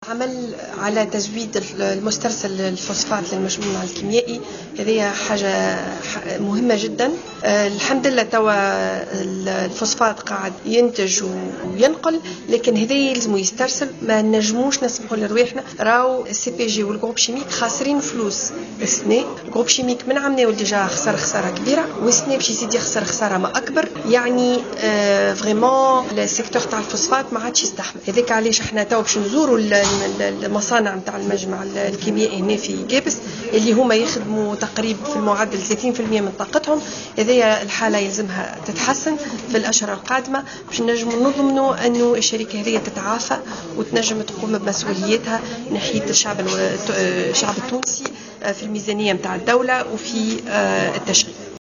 Elle a ajouté lors d’une rencontre de presse à l’occasion de la visite qu’elle a effectuée, samedi à la zone industrielle de Gbes que les efforts sont axés, actuellement, sur la résolution des problèmes qui risquent de retarder les travaux de ce projet.